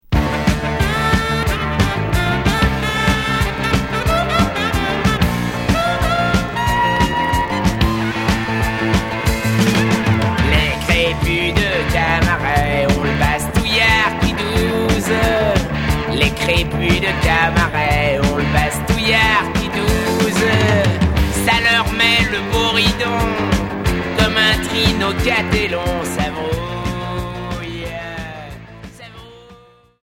Punk rock